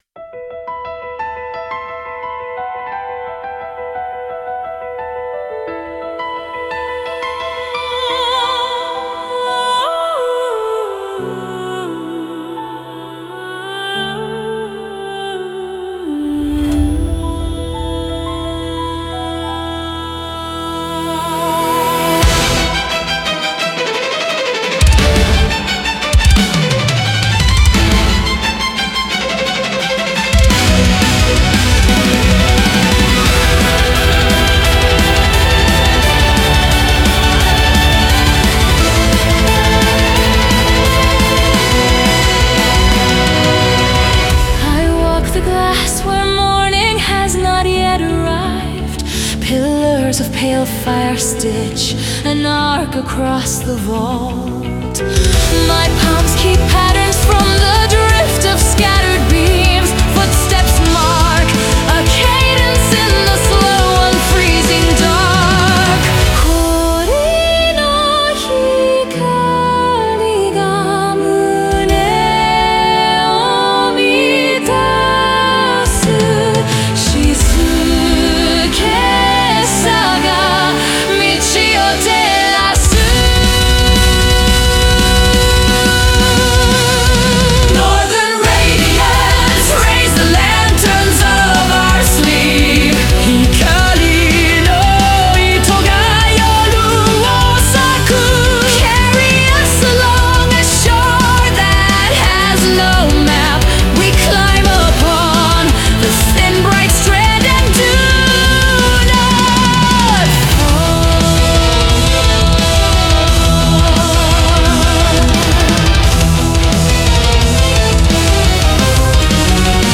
Symphonic Metal